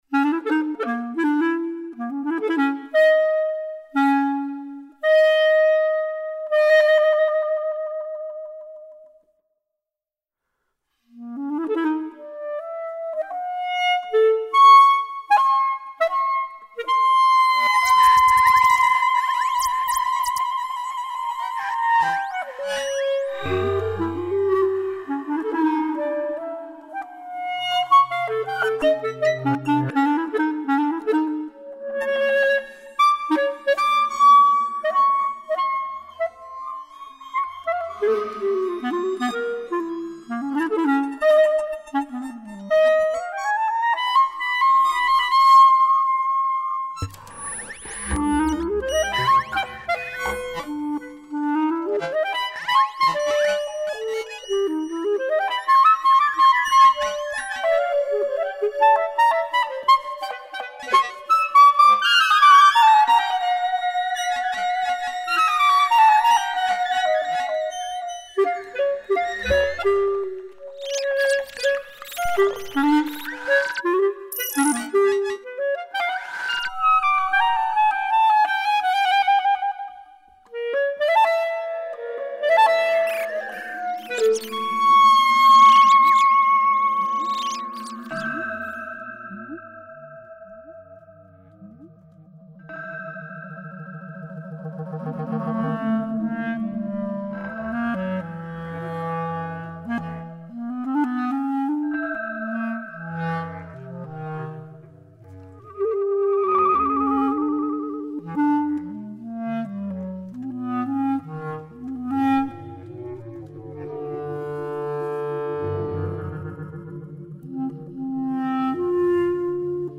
Pour clarinette et sons fixés en 5.1
Clarinette et dispositif 5.1 Durée
Version transaural. https